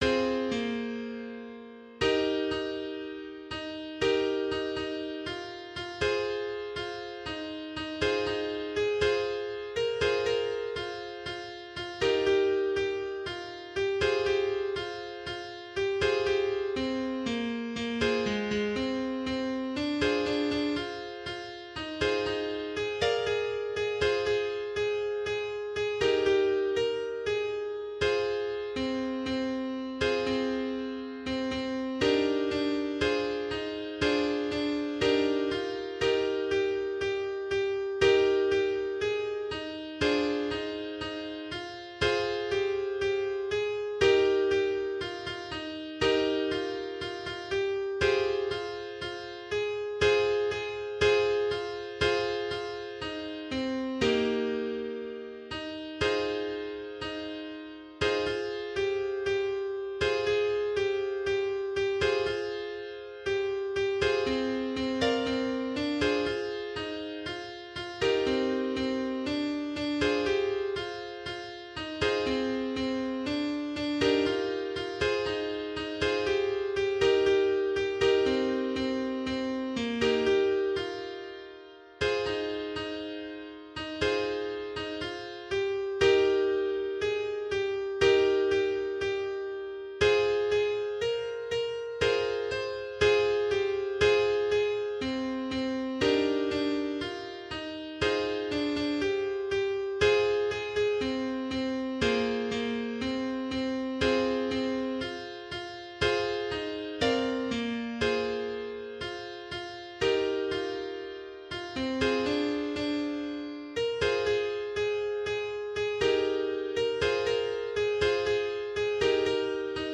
Those that are just a piano are the MIDI processed through LMMS without any fiddling with the instruments.